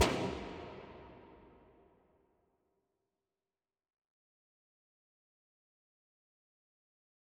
MDMV3 - Hit 2.wav